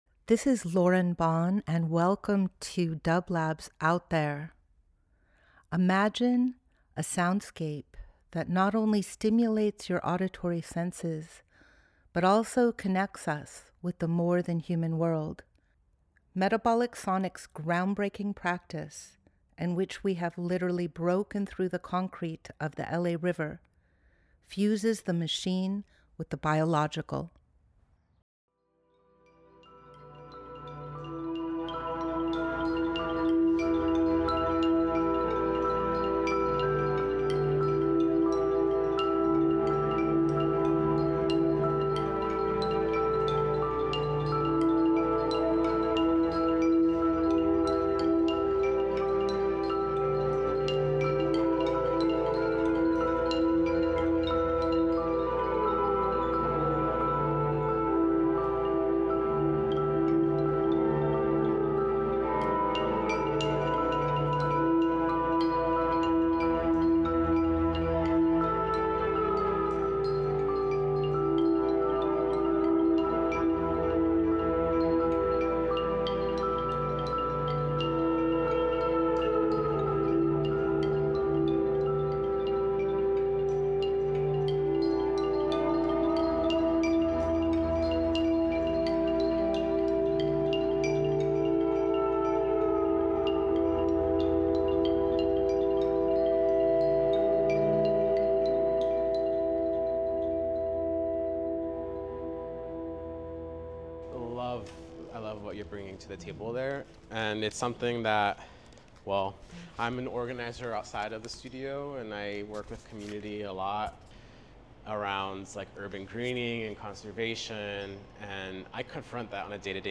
Each week we present a long-form field recording that will transport you through the power of sound.
We continue where we left off last week, with an introduction to, and a community discussion about Metabolic Studio’s project Moving Mountains, which redistributes healthy topsoil from landslides in the public art form Meandros. This discussion took place on the tour at Metabolic Studio on June 20, 2025. The discussion is interspersed with archived improvised music by Metabolic Sonics and also the Interdependence Choir.